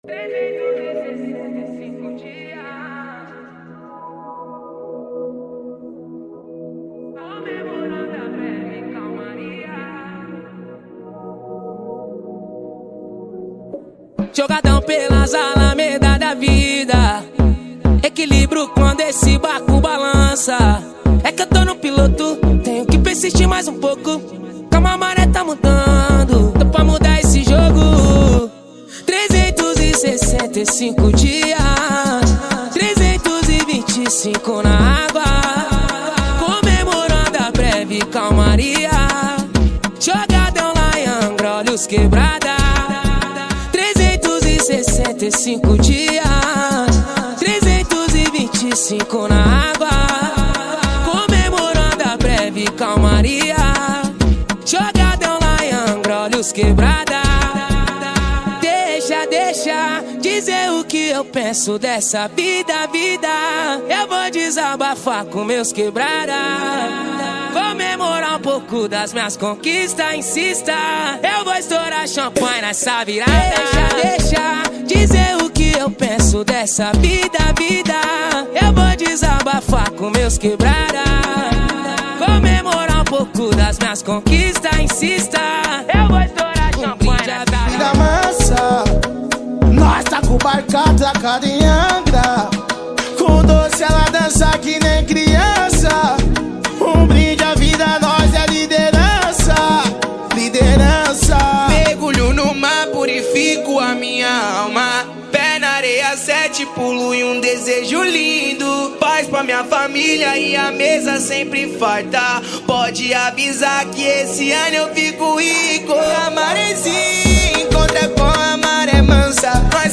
2024-12-19 12:03:19 Gênero: Funk Views